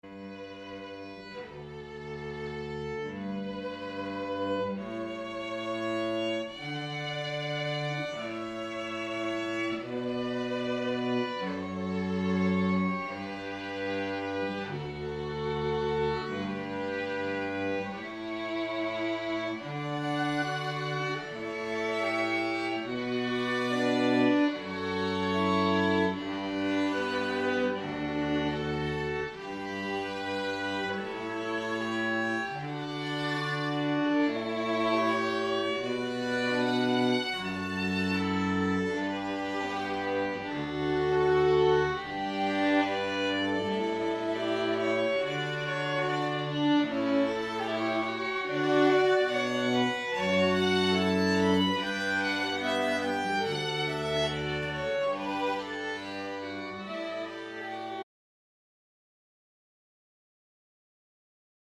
We have made a home recording of some snippets of the most popular Processionals and Recessionals as an audio aid in making your selections.